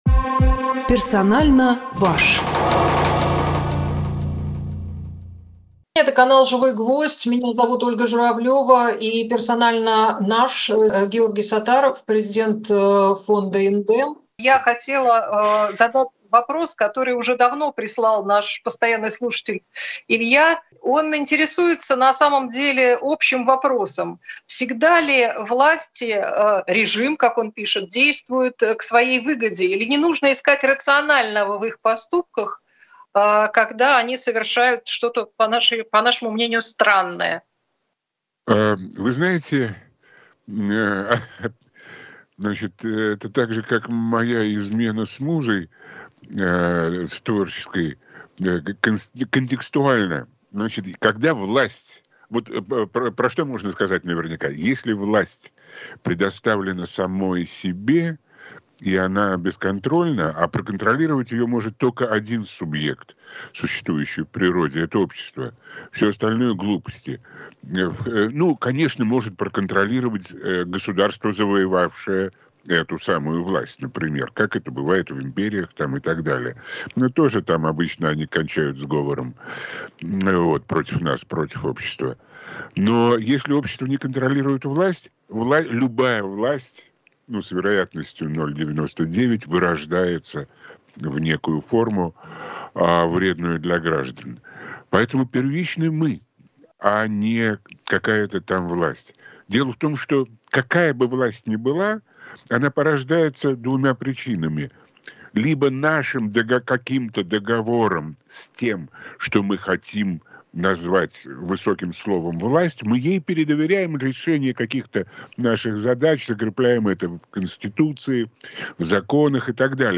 Георгий Сатаров политолог